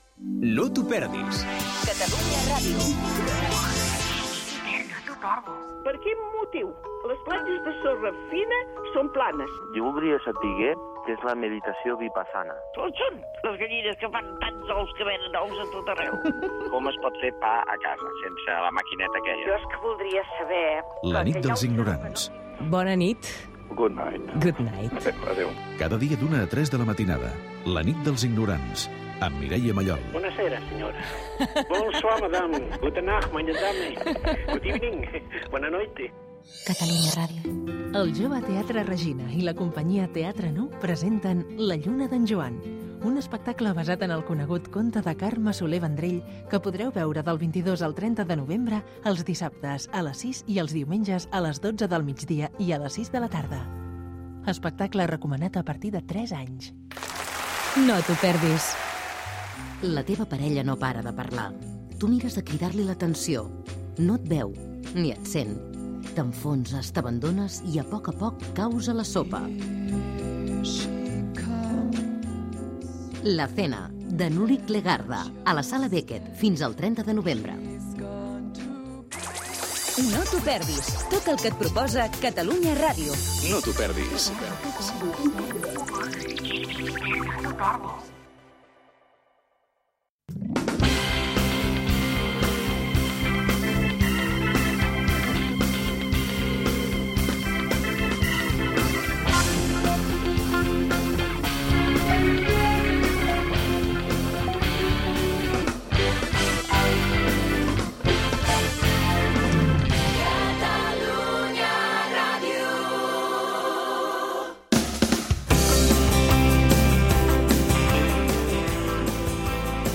notícies curioses: les fotografies del telèfon mòbil, sostenidors per a homes, etc. Gènere radiofònic Entreteniment